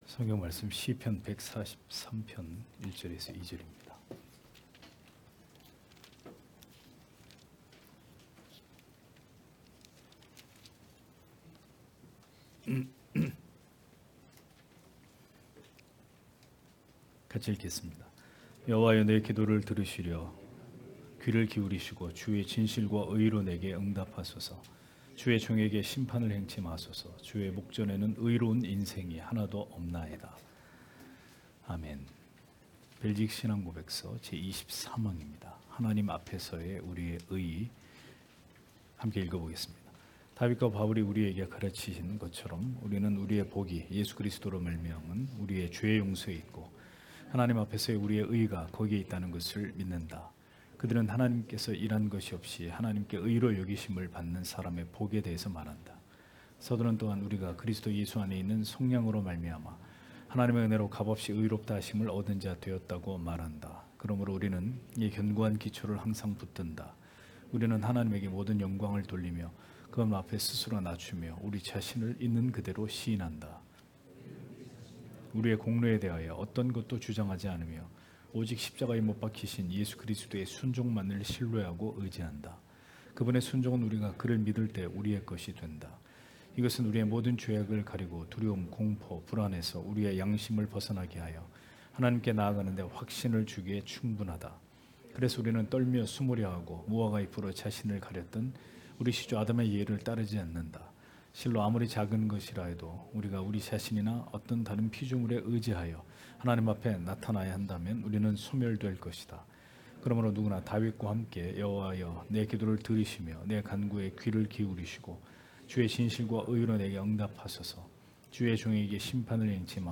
주일오후예배 - [벨직 신앙고백서 해설 24] 제23항 하나님 앞에서의 우리의 의 (시편143편 1-2절)